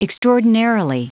Prononciation du mot extraordinarily en anglais (fichier audio)
Prononciation du mot : extraordinarily